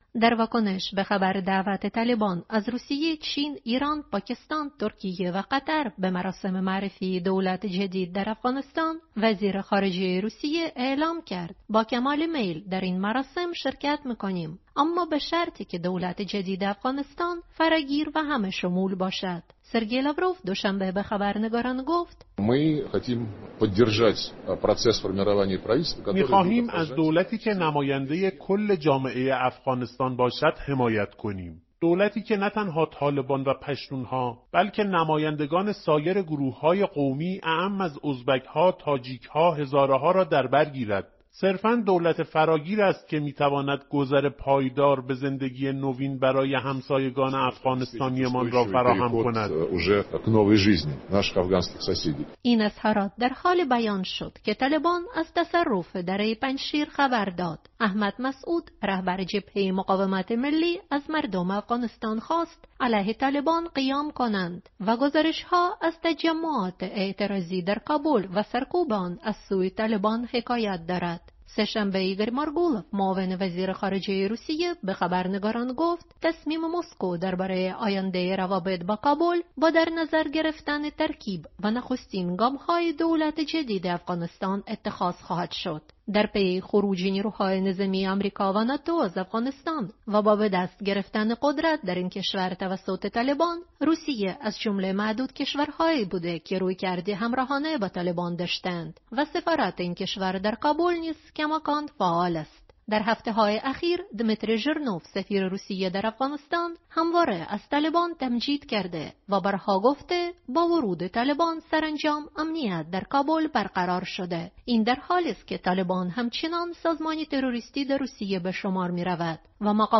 گزارشی